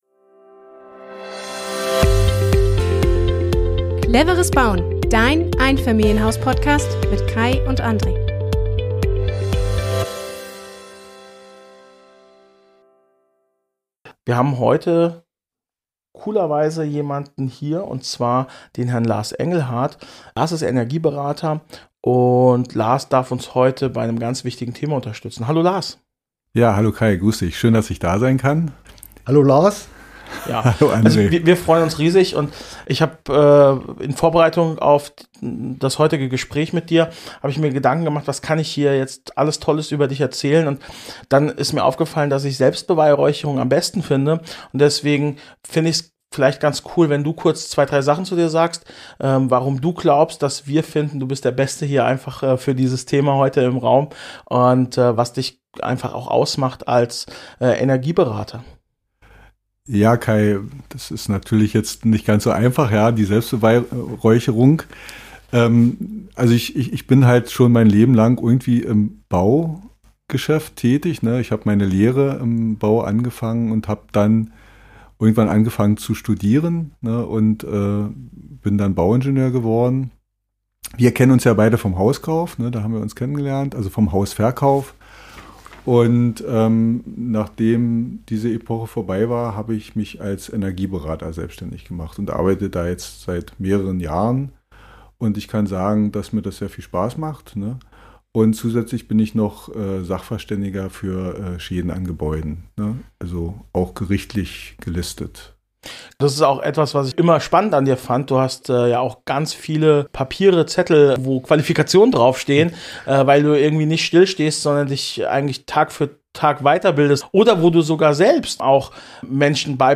Im Talk